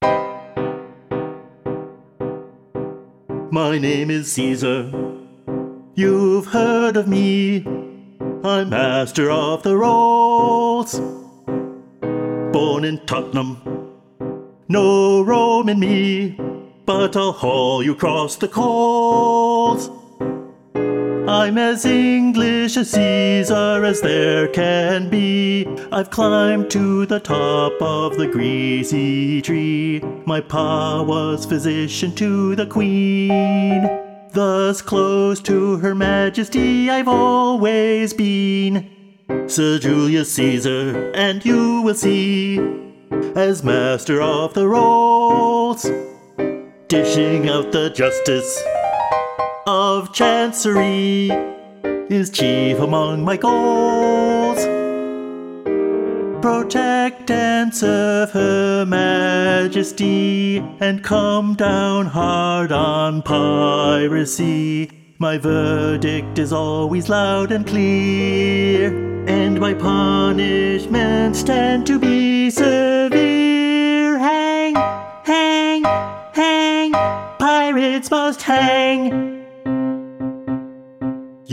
Hear the passage with just Caesar singing